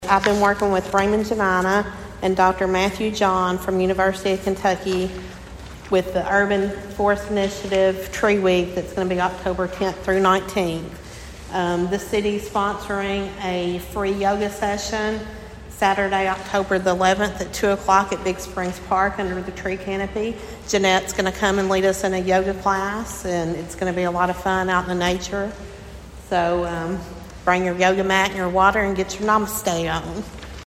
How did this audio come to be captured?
announced at Monday night’s city council meeting that a Yoga Class will be held this Saturday.